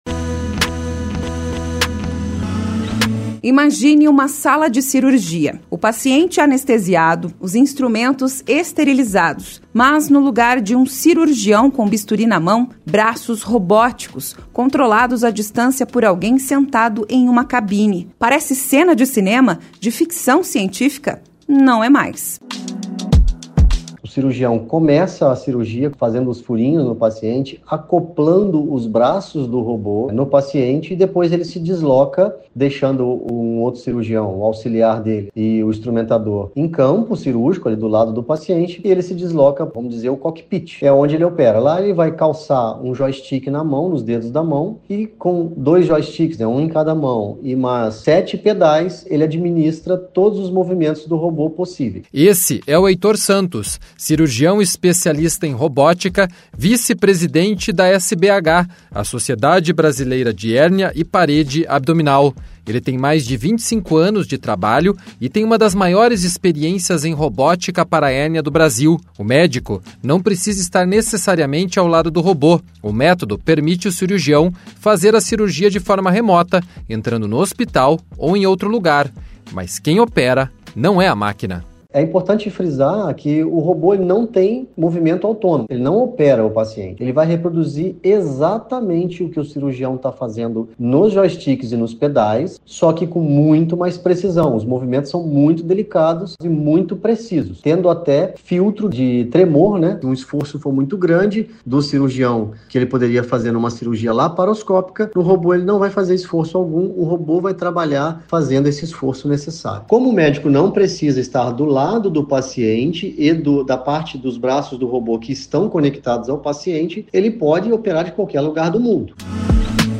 Reportagem 2 – A robótica